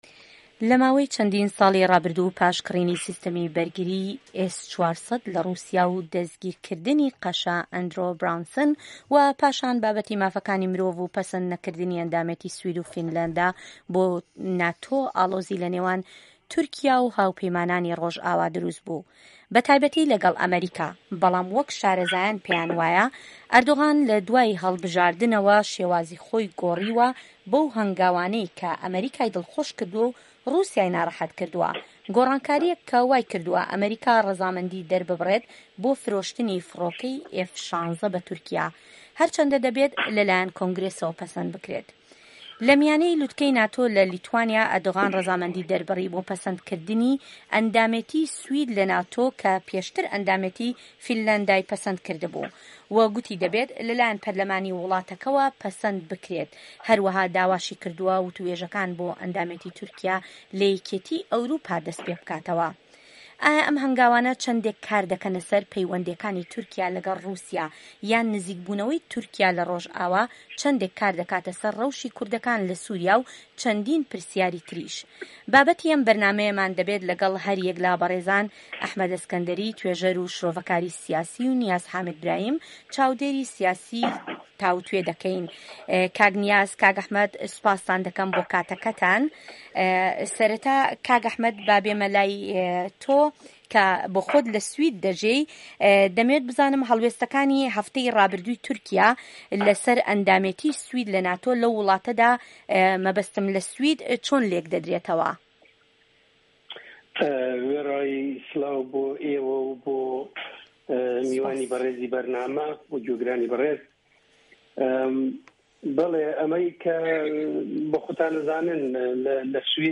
Round table Turkey